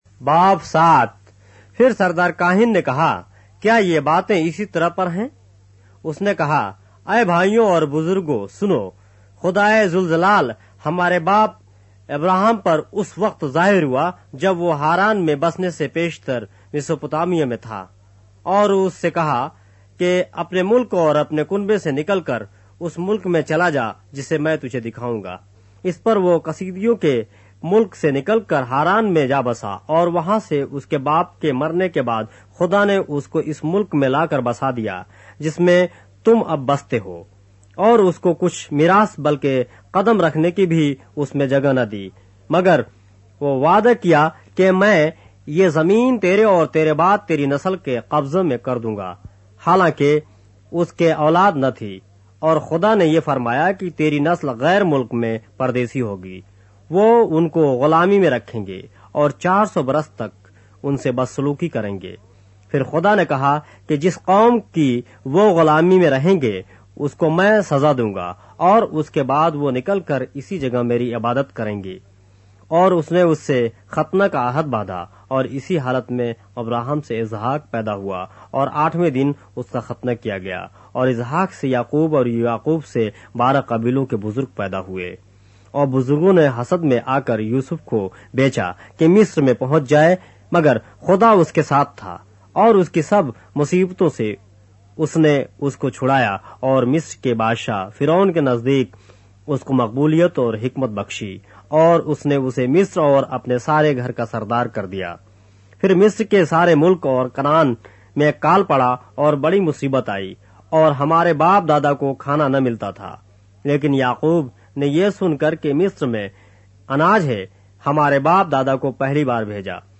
اردو بائبل کے باب - آڈیو روایت کے ساتھ - Acts, chapter 7 of the Holy Bible in Urdu